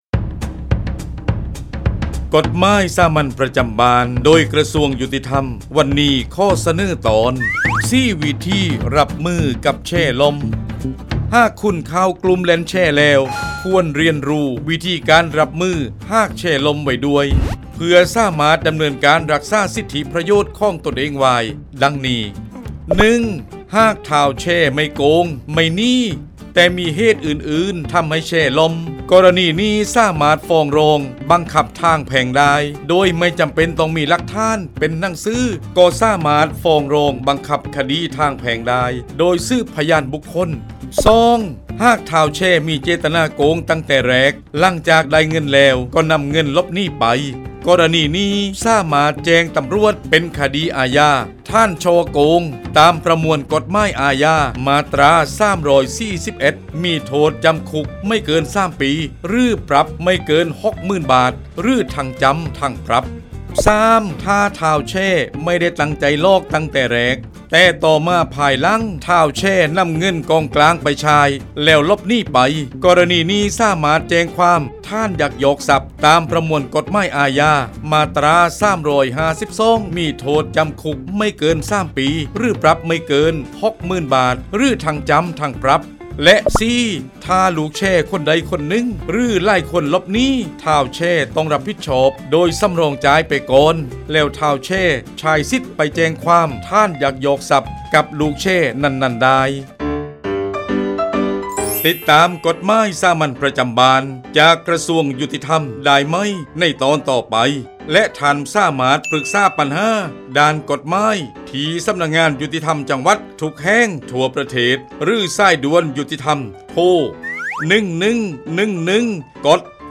กฎหมายสามัญประจำบ้าน ฉบับภาษาท้องถิ่น ภาคใต้ ตอน4 วิธีรับมือกับแชร์ล่ม
ลักษณะของสื่อ :   บรรยาย, คลิปเสียง